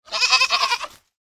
دانلود صدای بز 3 از ساعد نیوز با لینک مستقیم و کیفیت بالا
جلوه های صوتی